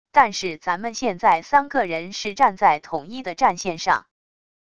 但是咱们现在三个人是站在统一的战线上wav音频生成系统WAV Audio Player